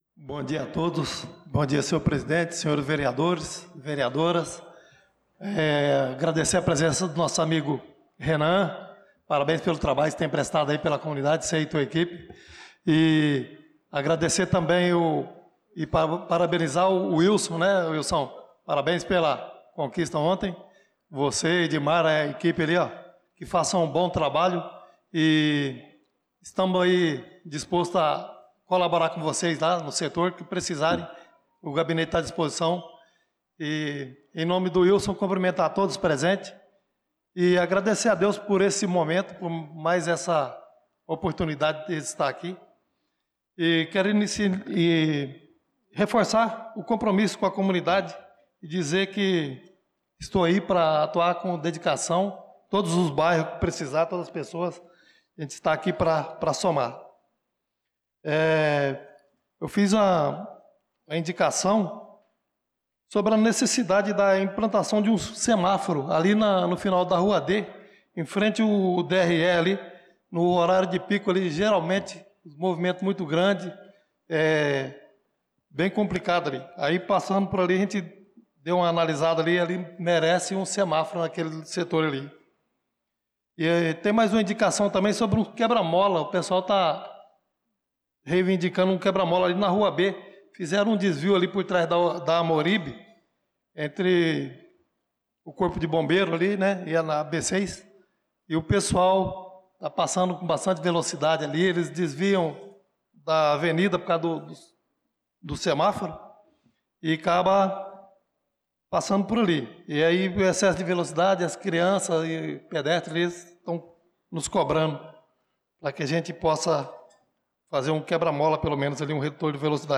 Pronunciamento do vereador Chicão Motocross na Sessão Ordinária do dia 28/04/2025